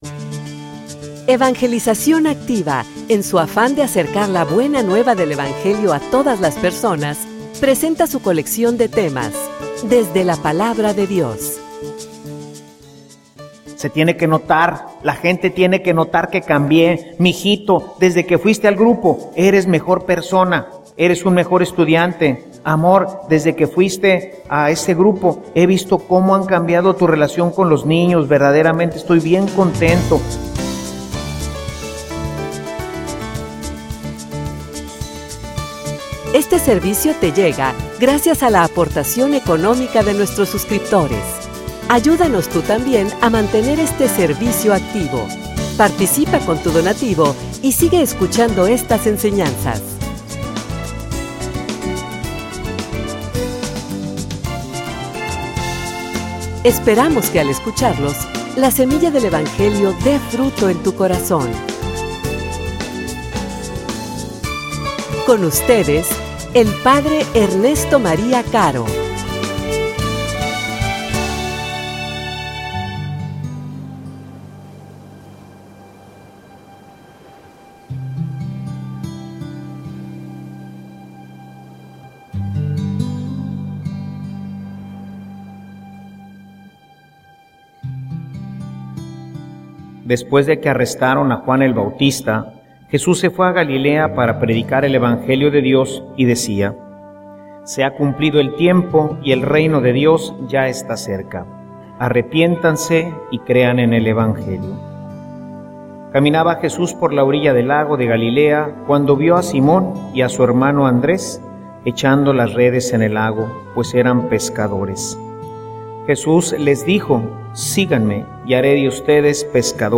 homilia_Conversion_que_se_nota.mp3